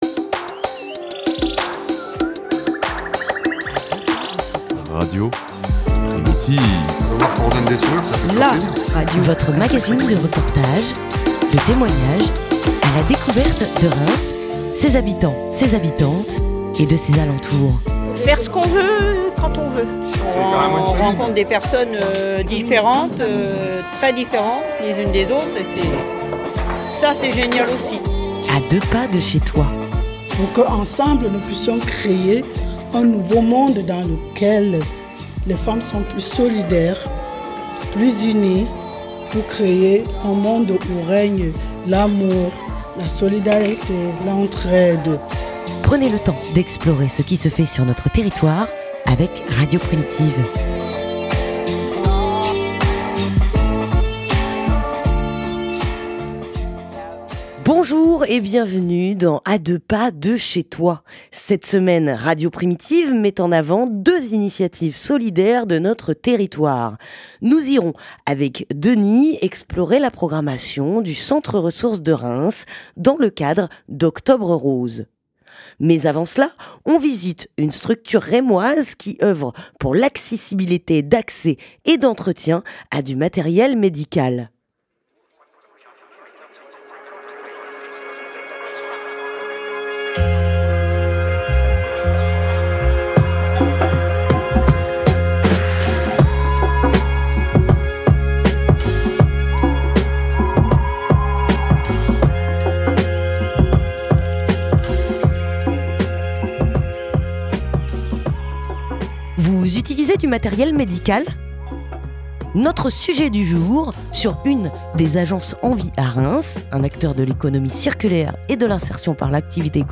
Par un après-midi ensoleillé, Radio Primitive s’est rendue dans une de ces structures d’insertion professionnelle qui a ses locaux dans le quartier de la Neuvillette.